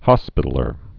(hŏspĭtl-ər)